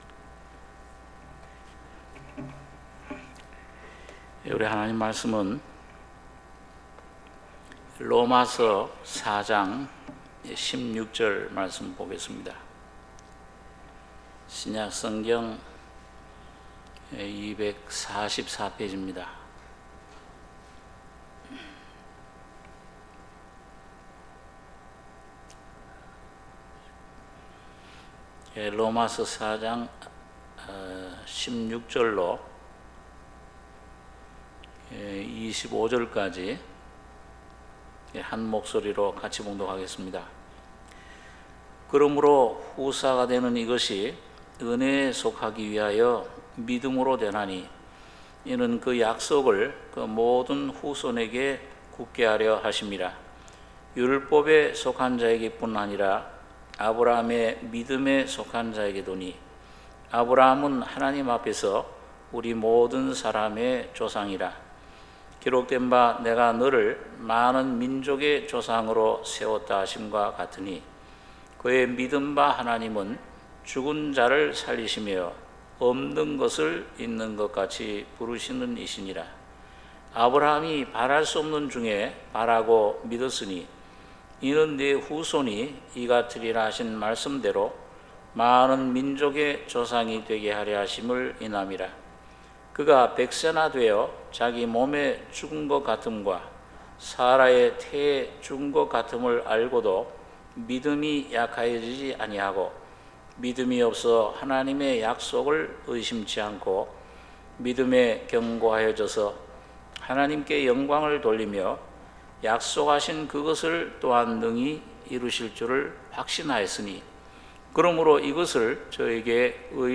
수요예배 로마서 4장16-25절